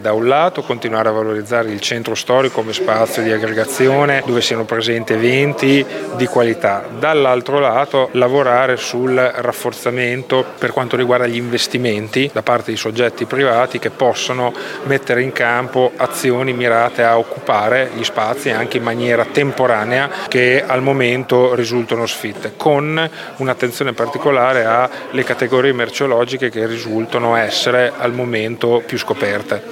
Cosa sta facendo il Comune di Modena per invertire questa tendenza? La risposta dell’assessore al centro storico Andrea Bortolamasi: